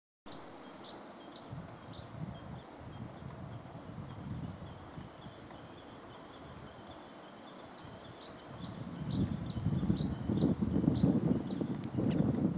Comesebo Patagónico (Phrygilus patagonicus)
Nombre en inglés: Patagonian Sierra Finch
Fase de la vida: Adulto
Localidad o área protegida: Parque Nacional Lanín
Localización detallada: Camino vehicular hacia lago Tromen
Condición: Silvestre
Certeza: Fotografiada, Vocalización Grabada